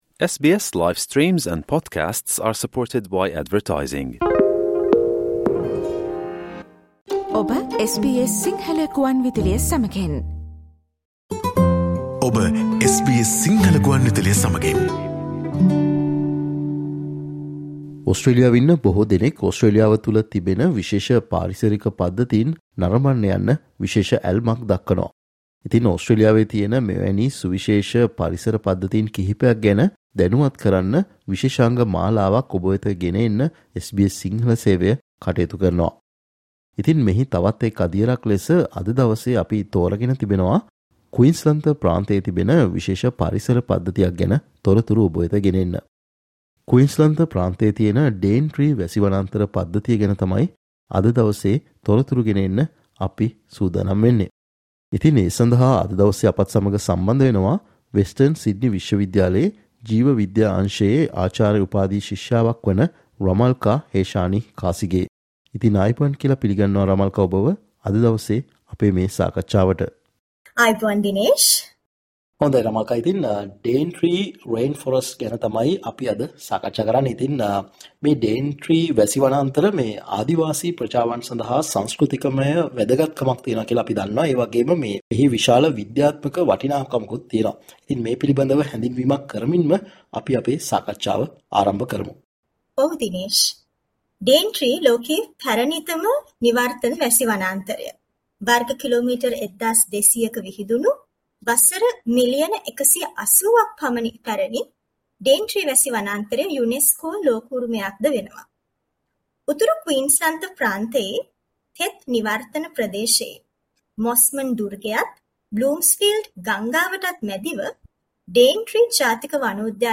SBS Sinhala Interview about the Daintree rainforest located in north Queensland.